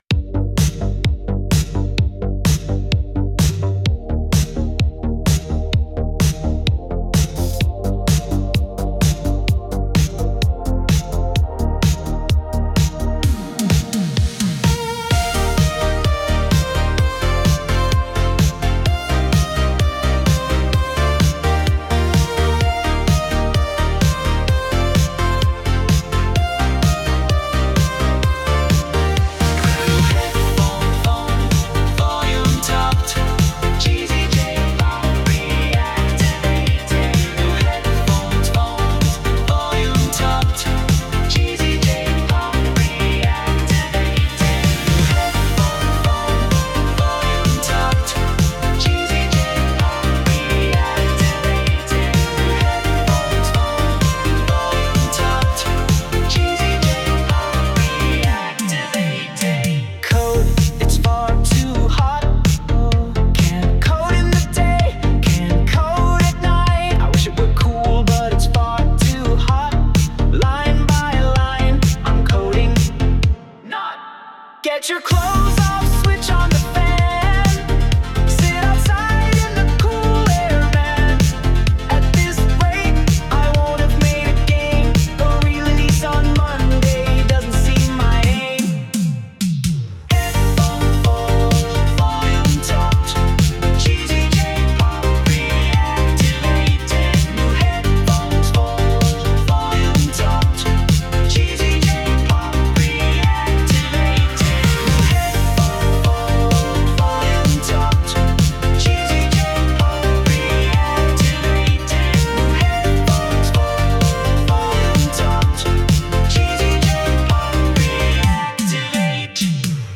They've been on charge, and will be doing the whole "Headphones On, Volume Up, Cheesy J-Pop, Activated" thing, later on.
Sung by Suno
New_Headphones_Volume_topped_(Cover)_(Remix)_mp3.mp3